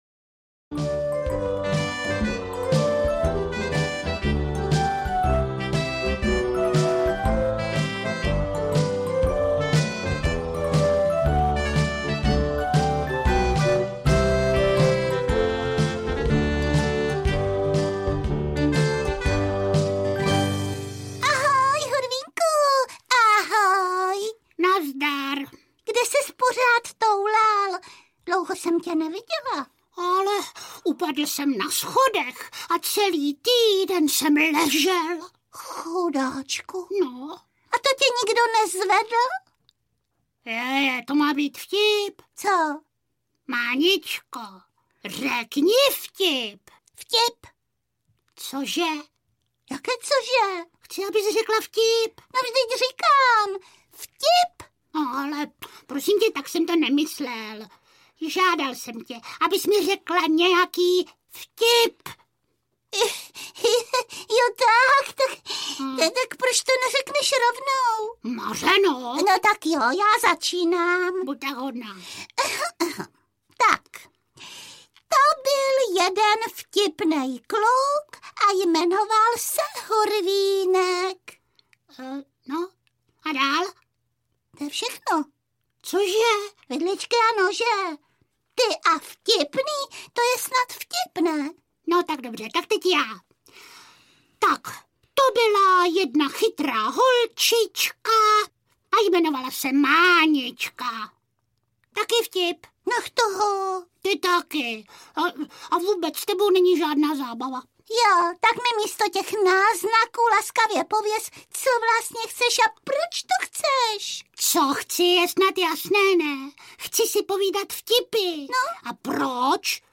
Hurvínkovo chichotání 3 audiokniha
Ukázka z knihy